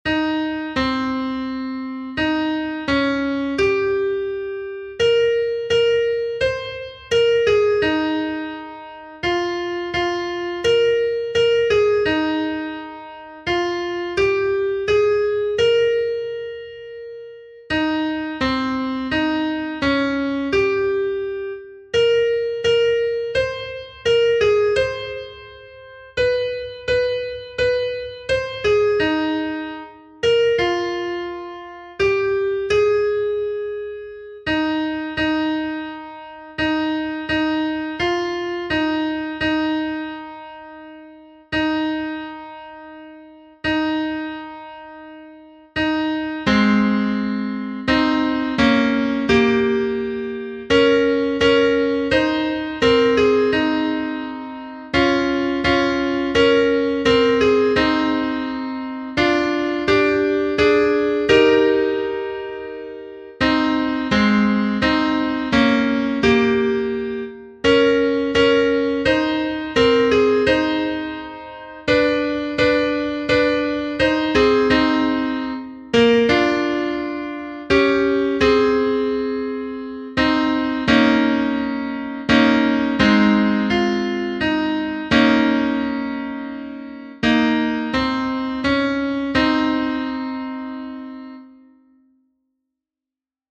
Note: the piano music I’m playing is slightly different than the sheet music above.
Words and Music by Fr. James B. Curry